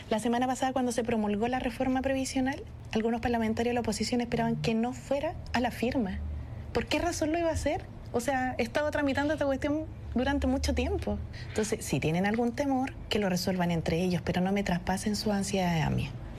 Posteriormente, en conversación con Radio Bío Bío de Concepción, la ministra Jara respondió, señalando que es evidente la preocupación de la candidata gremialista.